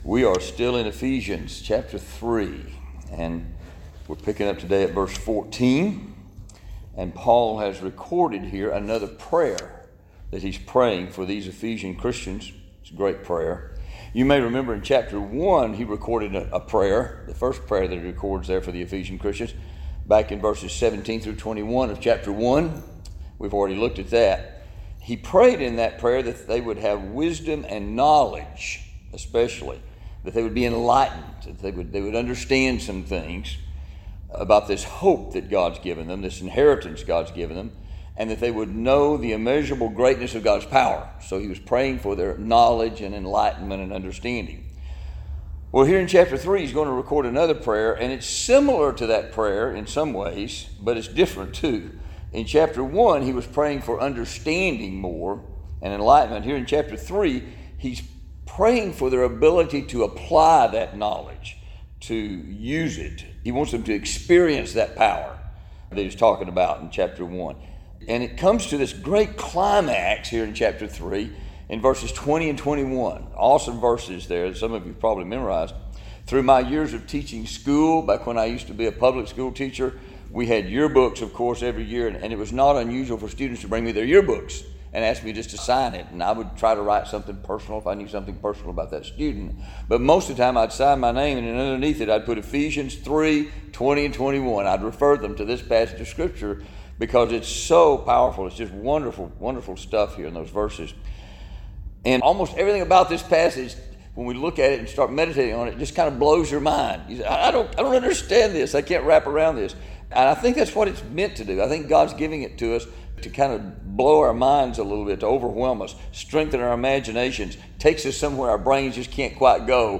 Reading of the extended metaphor essay, “My Heart, Christ’s Home” by Robert Munger.